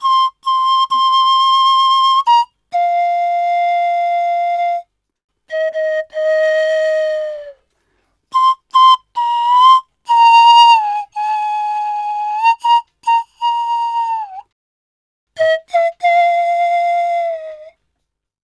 BAMBFLUTE.wav